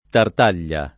tart#l’l’a] cogn. — propr. soprannome, in origine, quello del matematico Niccolò T. (m. 1557), da cui prende nome il triangolo del T. — anche cogn. vero e proprio, e nome di maschera della commedia dell’arte